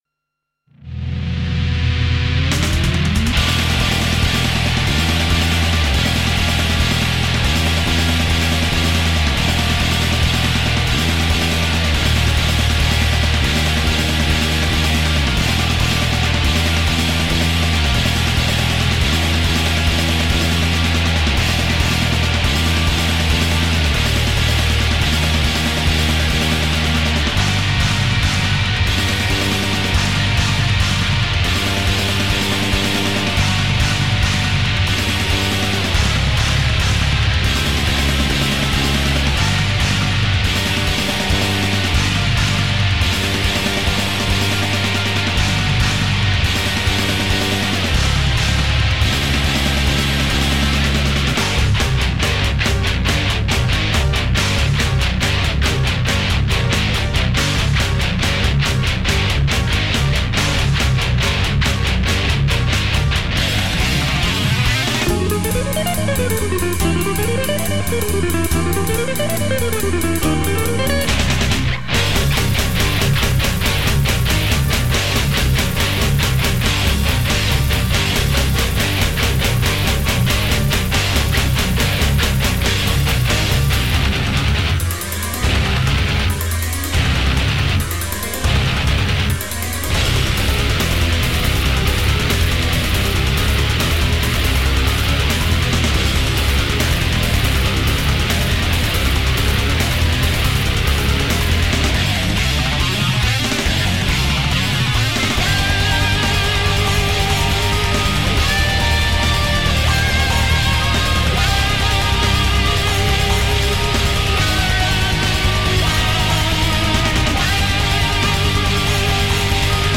Spanish guitarist and composer
instrumental Prog Rock/Metal style